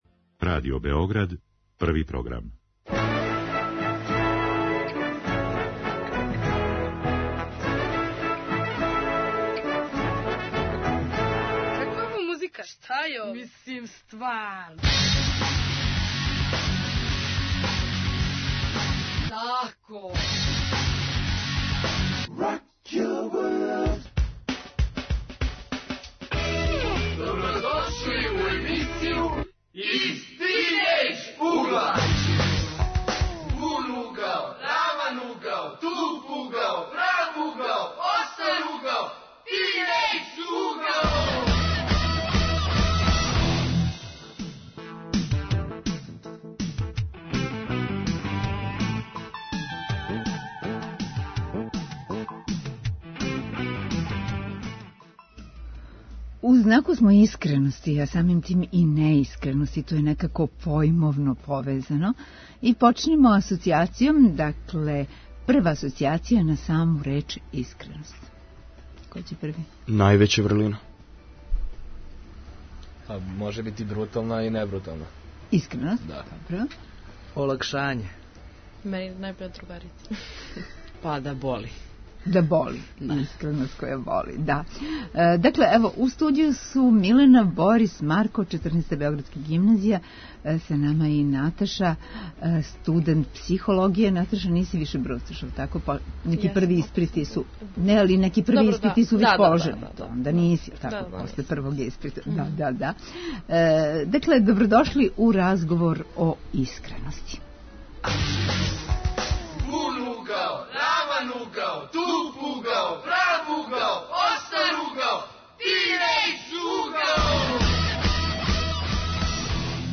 Гости у студију су средњошколци.